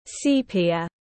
Màu nâu đen tiếng anh gọi là sepia, phiên âm tiếng anh đọc là /ˈsiː.pi.ə/.
Sepia /ˈsiː.pi.ə/